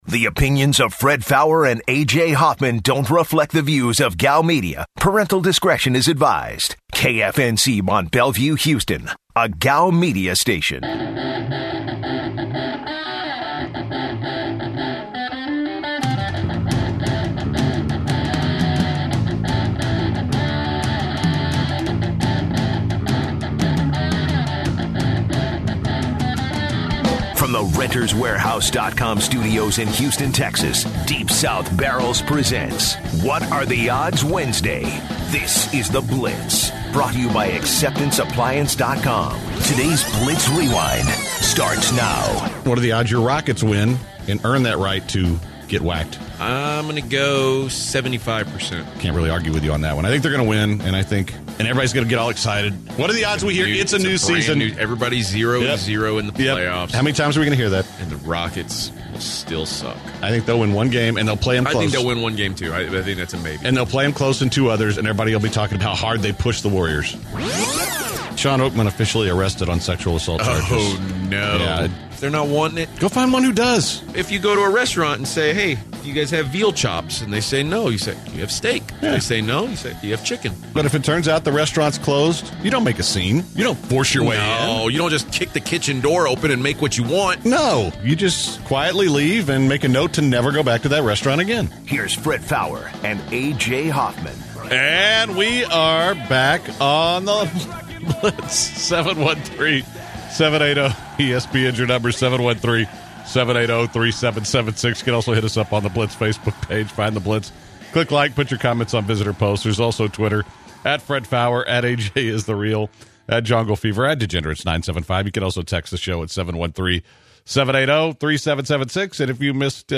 The guys take some calls from the Blizters regarding their best What are the Odds questions.